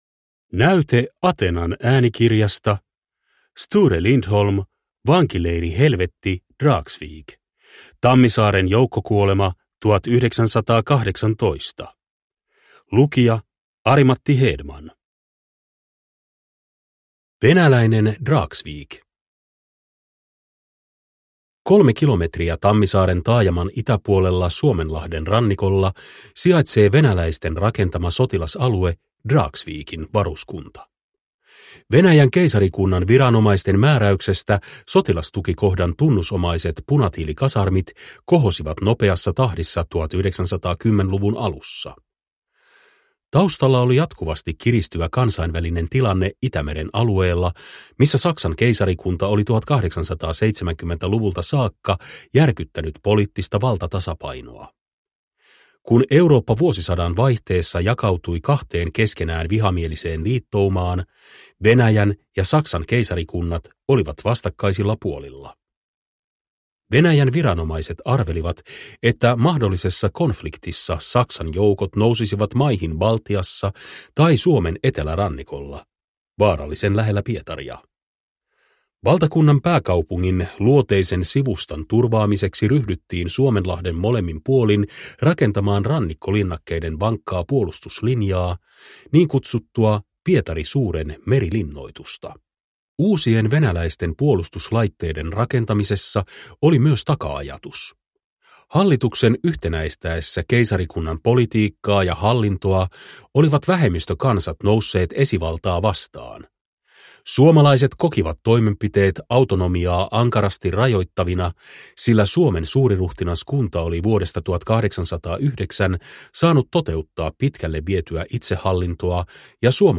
Vankileirihelvetti Dragsvik – Ljudbok – Laddas ner